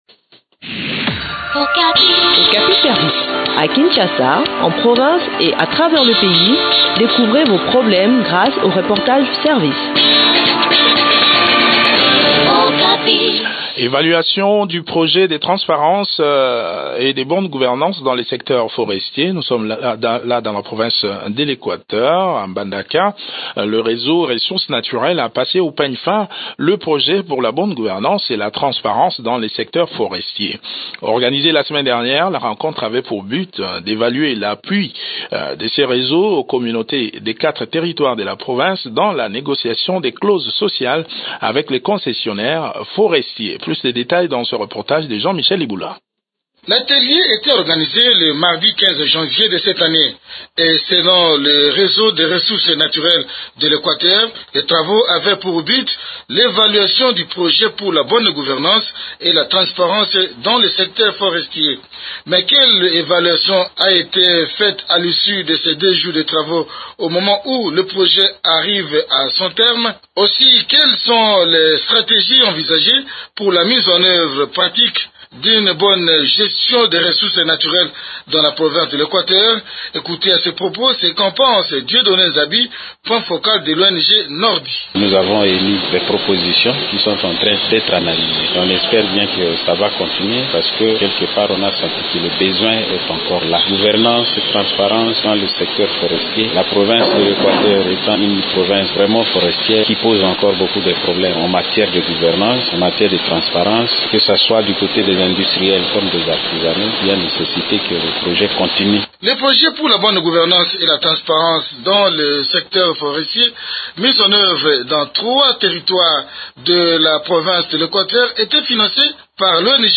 Objectif: évaluer l’appui de cette plateforme aux communautés engagées dans la négociation des clauses sociales avec les exploitants forestiers. Le point du sujet dans cet entretien